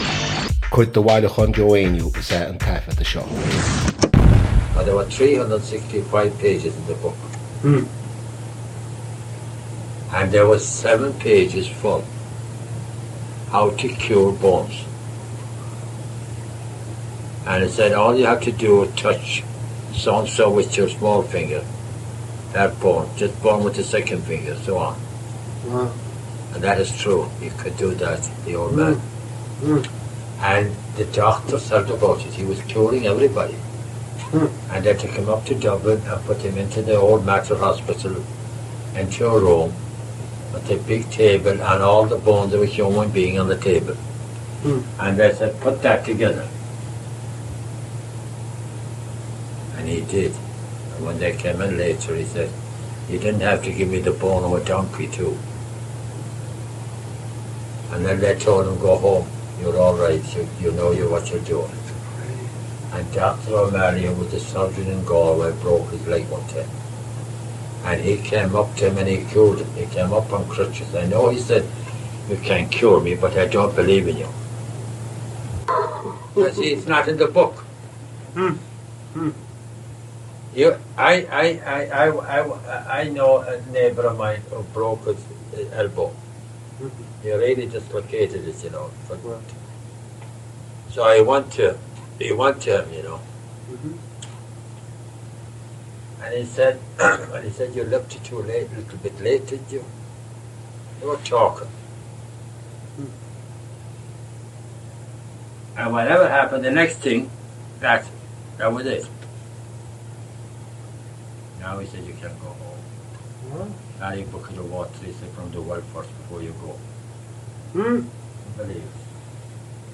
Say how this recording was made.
• Suíomh an taifeadta (Recording Location): Wesleyan University, Middletown, Connecticut, United States of America. • Ocáid an taifeadta (Recording Occasion): evening class.